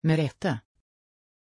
Aussprache von Merete
pronunciation-merete-sv.mp3